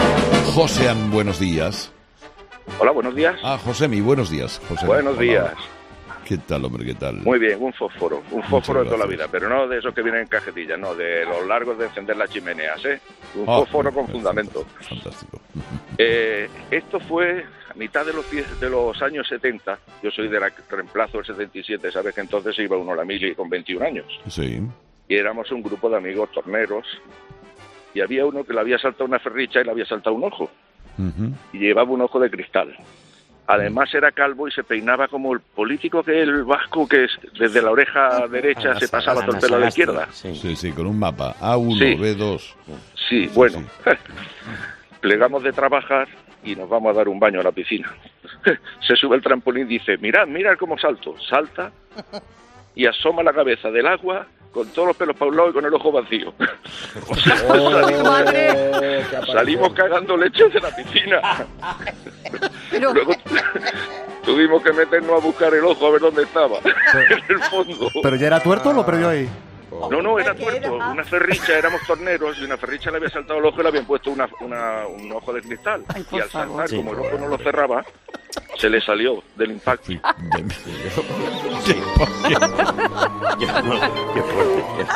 si bien luego tuvieron que meterse “a buscar el ojo a ver dónde estaba”, ha relatado entre risas.